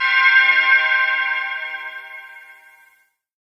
Met Gala Bells.wav